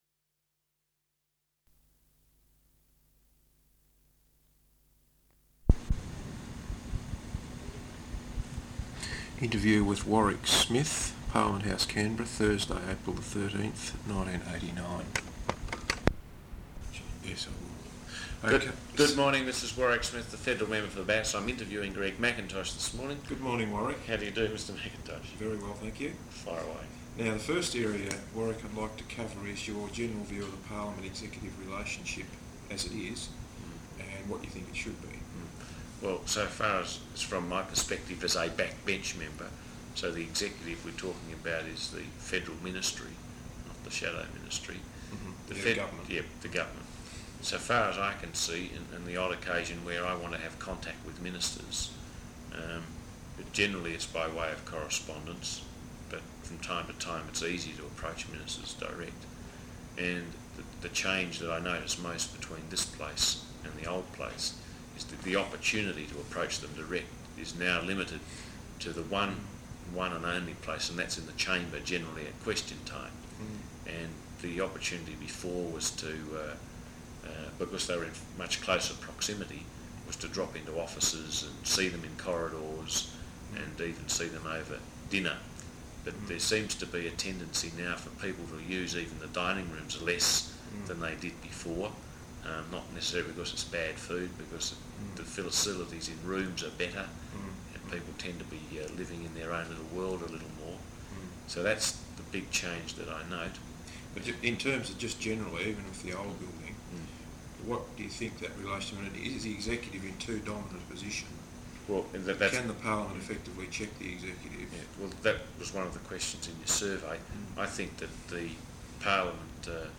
Interview with Warwick Smith, Parliament House Canberra, Thursday April 13th 1989.